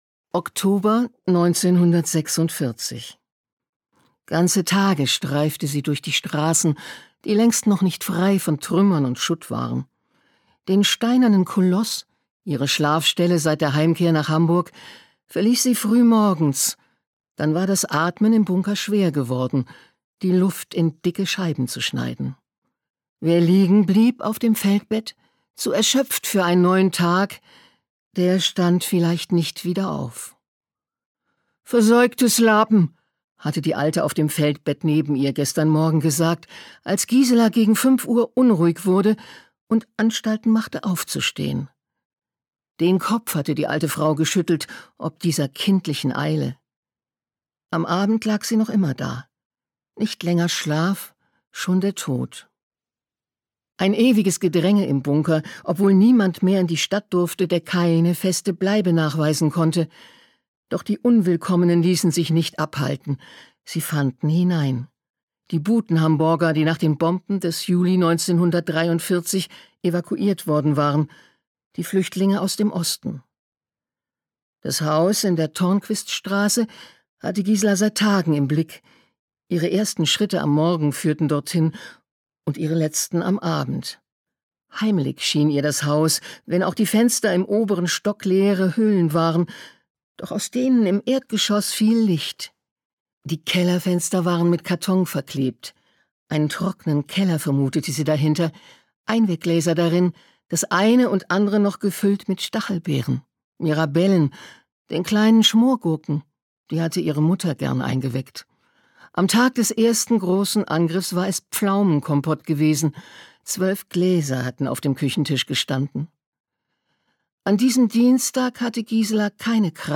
Carmen Korn (Sprecher)
2025 | 2. Auflage, Ungekürzte Ausgabe
Als Interpretin ihrer eigenen Romane schafft sie eine unwiderstehliche Atmosphäre.